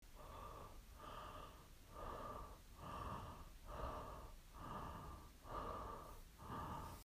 breathing.m4a